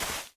Minecraft Version Minecraft Version snapshot Latest Release | Latest Snapshot snapshot / assets / minecraft / sounds / block / suspicious_sand / place2.ogg Compare With Compare With Latest Release | Latest Snapshot